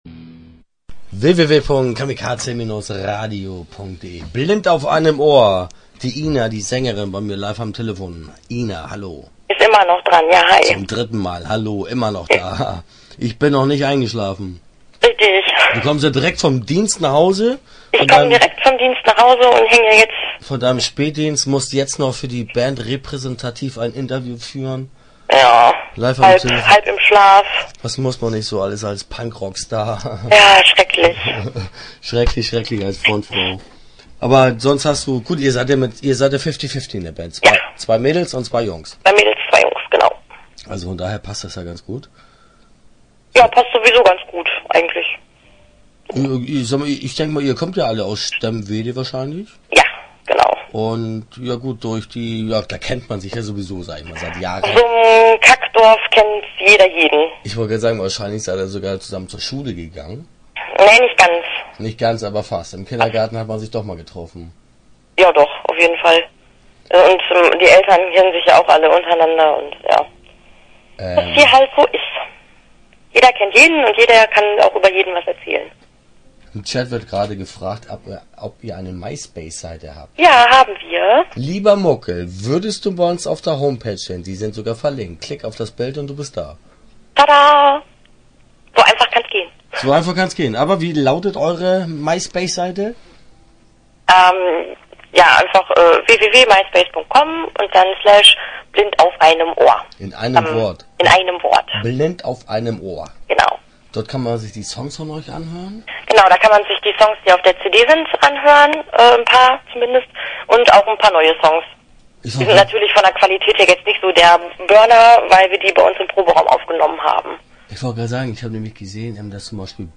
Interview Teil 1 (7:45)